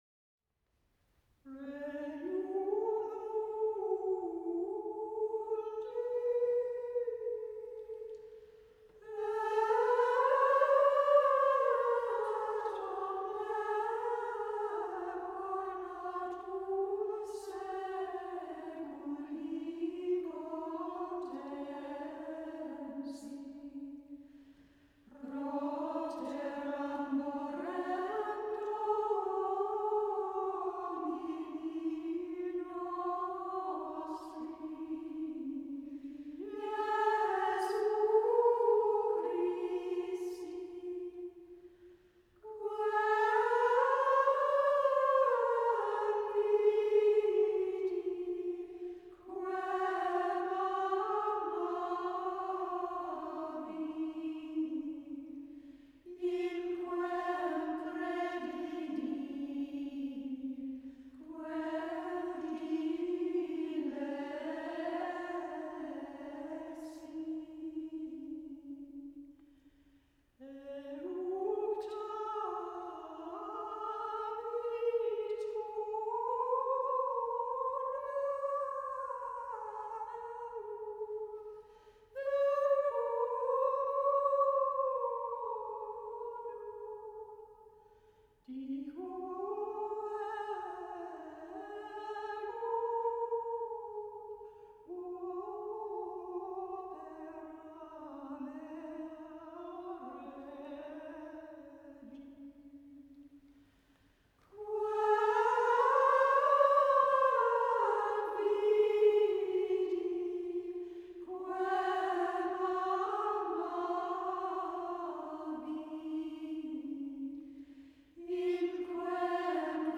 Stanbrook Abbey near Worcester is the current home of an order of Benedictine nuns. The Abbey chapel is a wonderful space and I was able to make this recording for a theatre show about a past Abbess, Dame Laurentia McLachlan and her relationship with George Bernard Shaw and Sir Sydney Cockerell.
Ambisonic
Ambisonic order: F (4 ch) 1st order 3D
Microphone name: Soundfield ST250
Array type: Tetrahedron